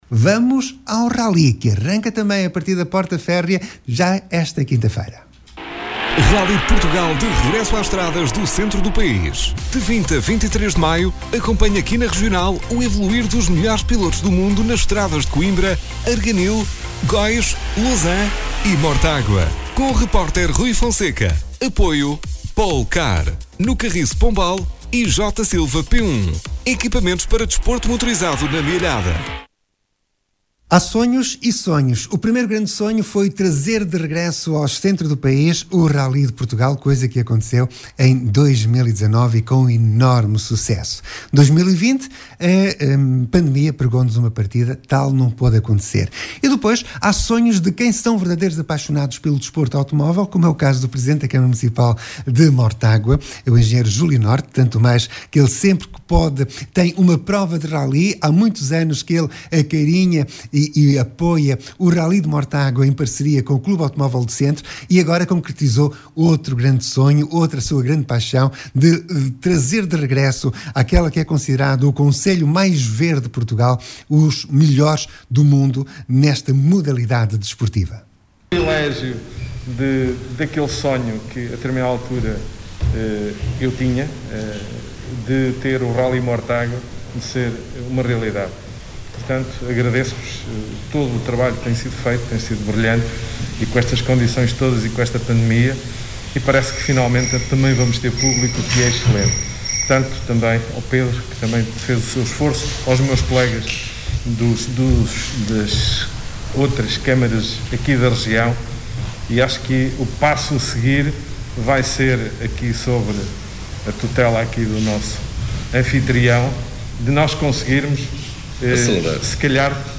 Pode recordar aqui as declarações de: Júlio Norte, presidente da Câmara Municipal de Mortágua; Manuel Machado, presidente da Câmara Municipal de Coimbra; Lurdes Castanheira, presidente da Câmara Municipal de Góis; Ana Ferreira, vereadora da Câmara Municipal da Lousã e Luís Paulo Costa, presidente da Câmara Municipal de Arganil.